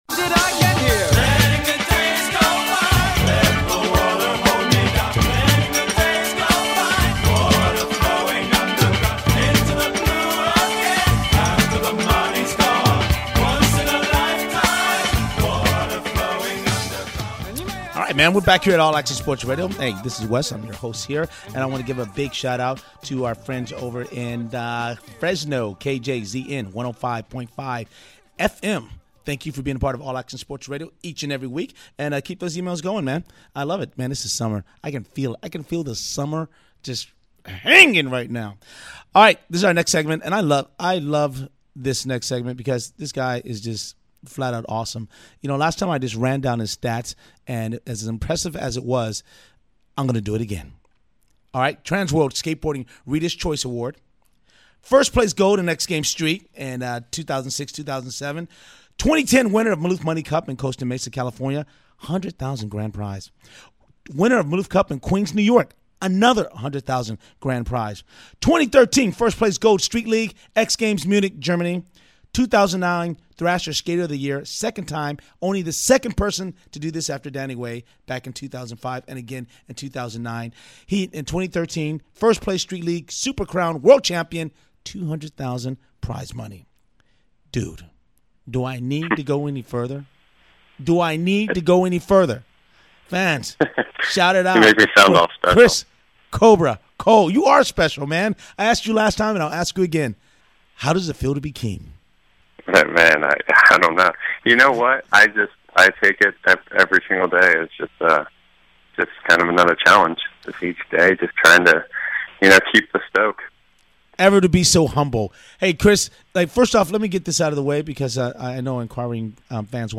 AASR Athlete Interview with Pro Skateboarder Chris Cobra Cole